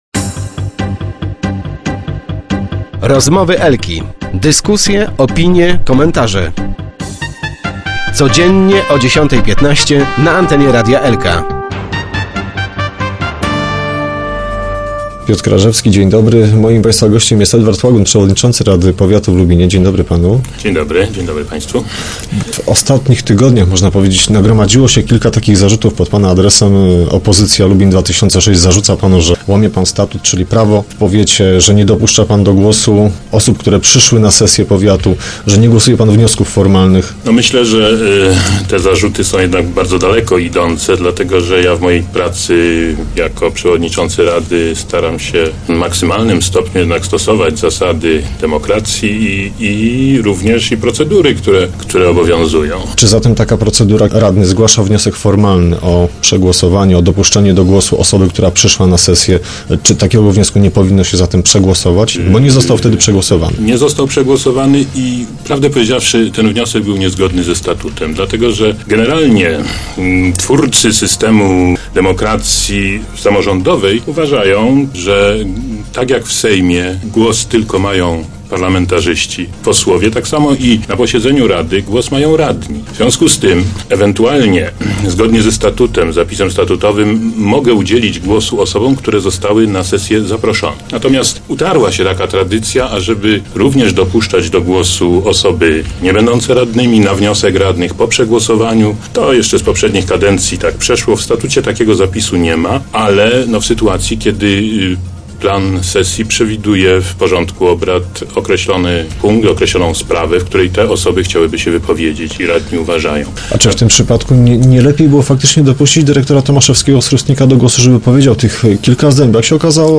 Start arrow Rozmowy Elki arrow Łagun - nie jestem przykuty do fotela
Przewodniczący nie dał się sprowokować, ale w naszym studiu przyznał - Nie zastanawiałem się nad rezygnacją, ale też nie muszę pełnić funkcji za wszelką cenę.